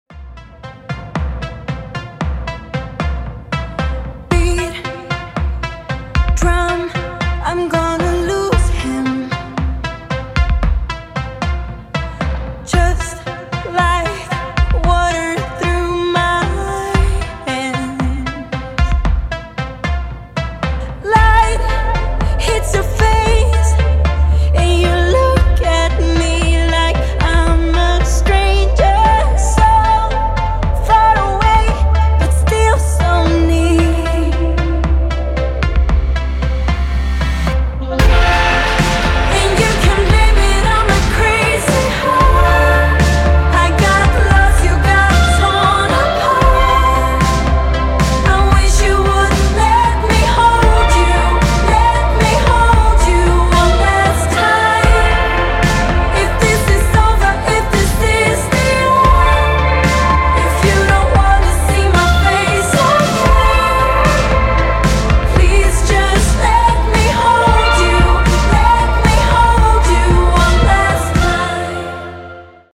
• Качество: 224, Stereo
поп
женский вокал
спокойные
медленные
романтичные